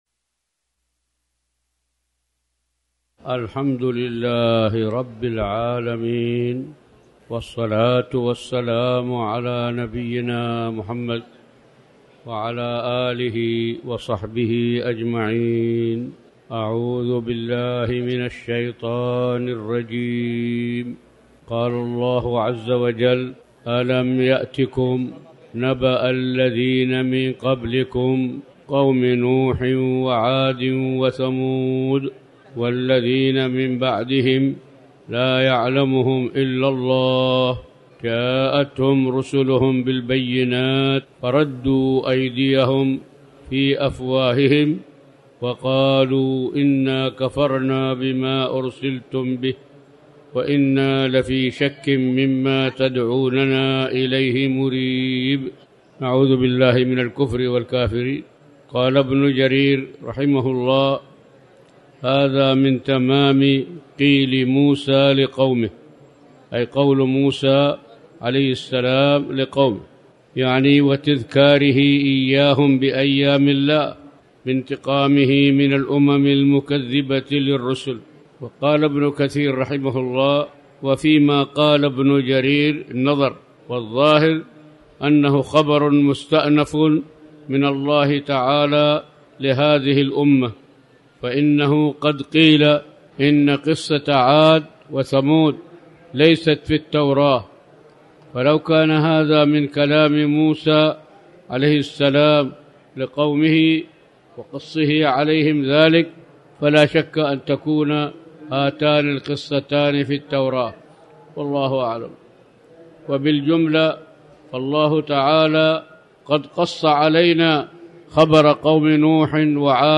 تاريخ النشر ١٦ ربيع الأول ١٤٤٠ هـ المكان: المسجد الحرام الشيخ